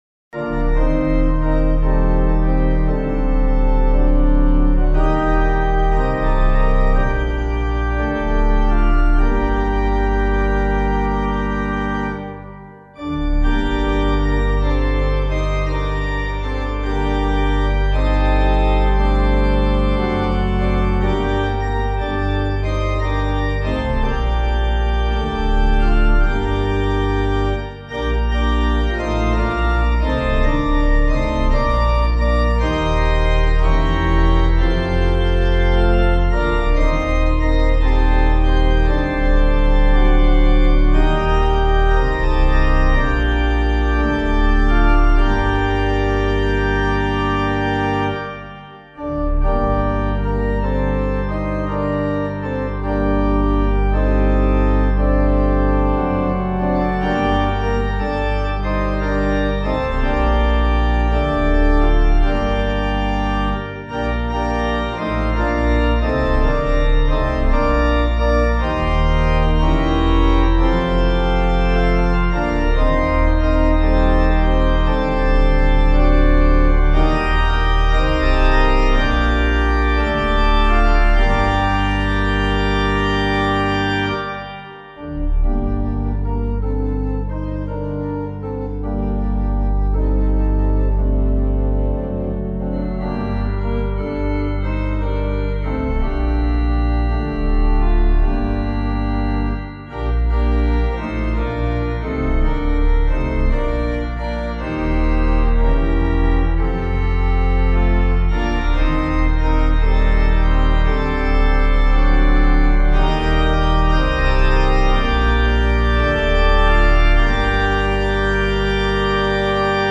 Composer:    traditional Basque carol melody; harmonized by Charles E. Pettman, 1866-1943.
organpiano